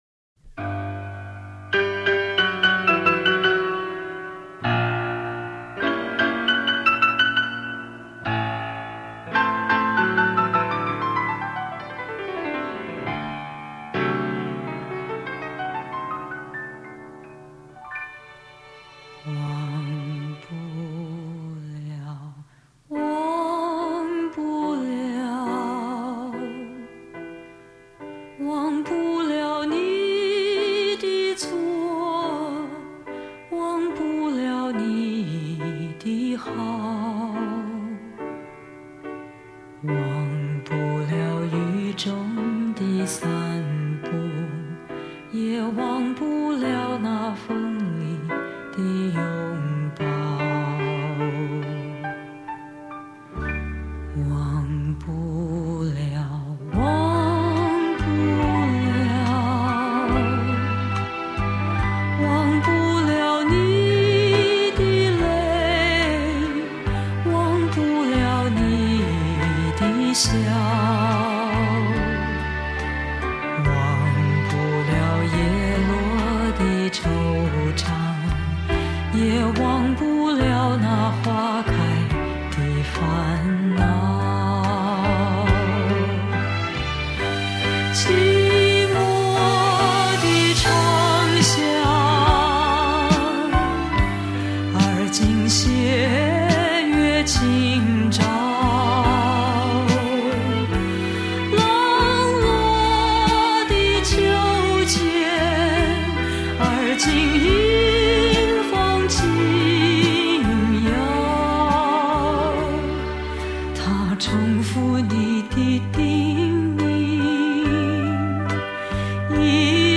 怀旧金曲